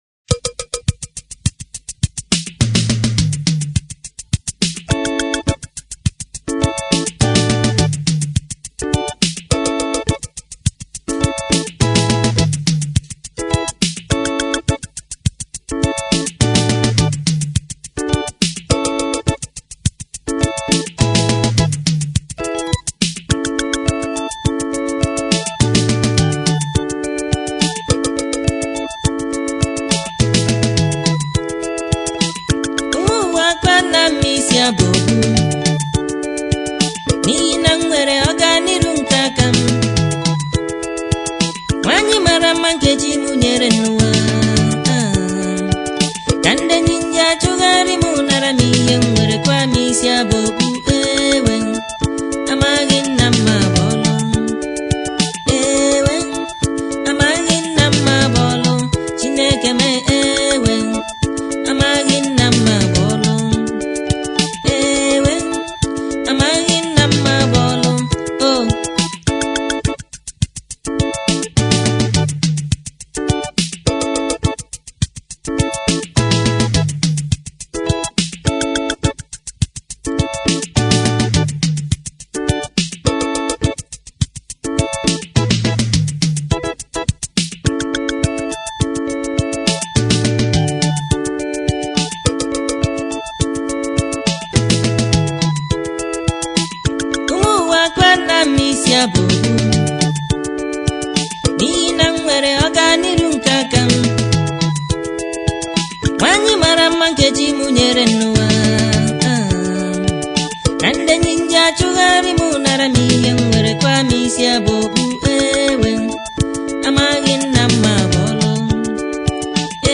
October 16, 2024 admin Highlife Music, Music 0